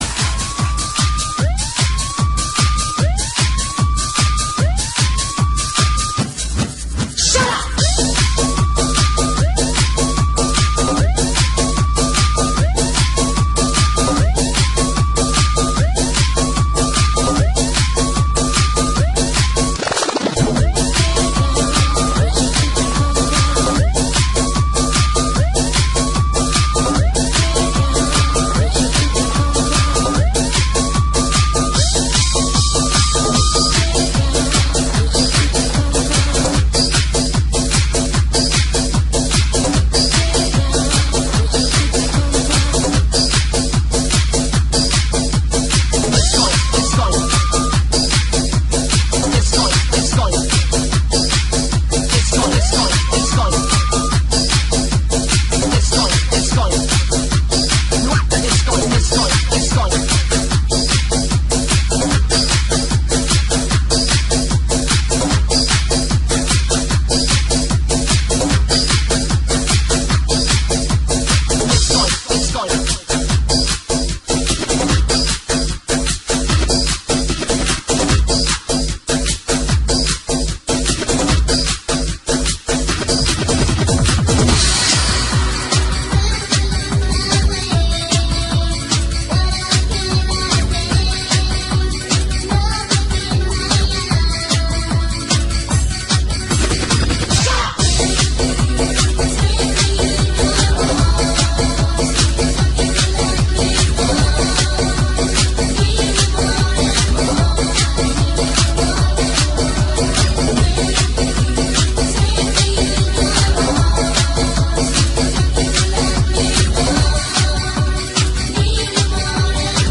Stara klubowa nuta - Muzyka elektroniczna
Stara klubowa nuta